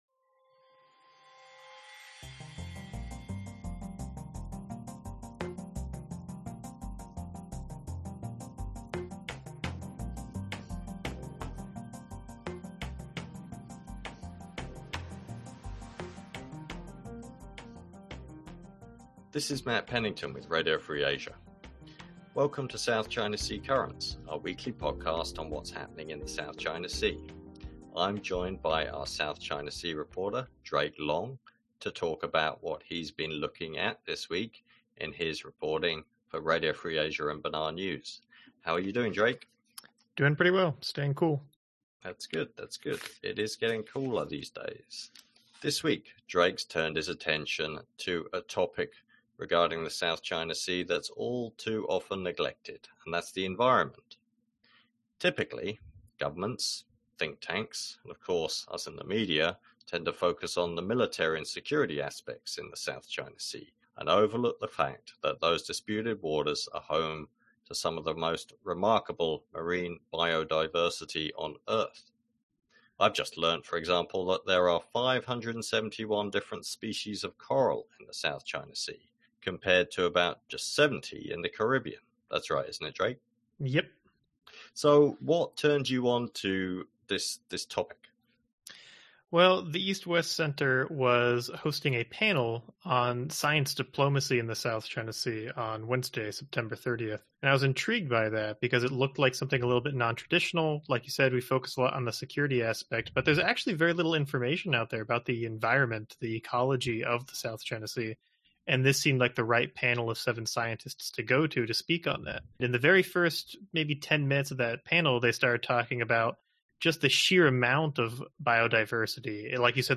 The Ecology of the South China Sea: Interview